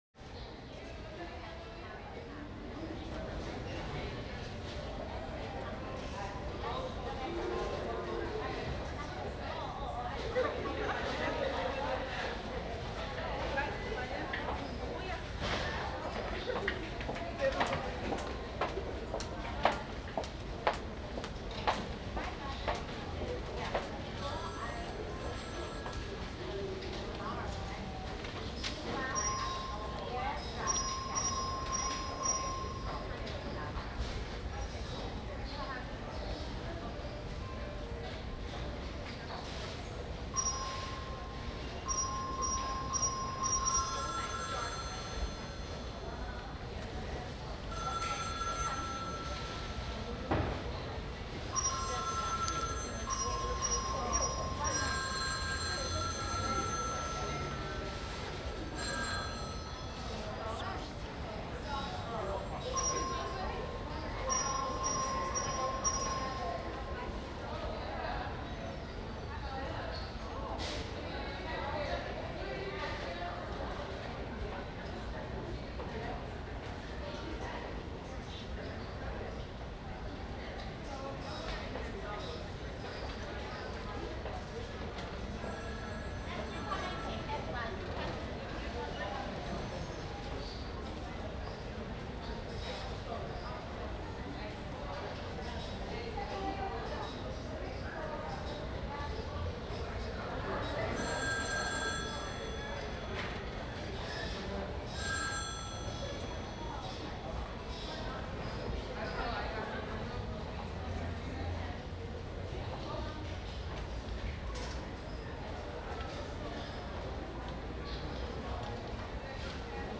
Department Store Ambience - Busy Sound
ambience